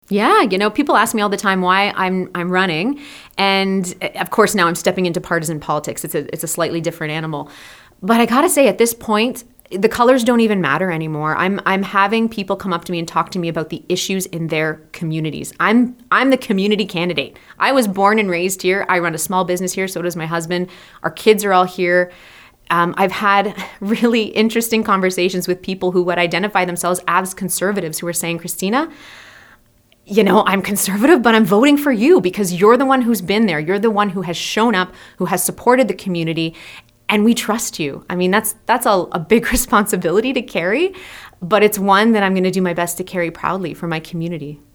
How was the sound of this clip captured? She chose to join us in-person at our Milton studios.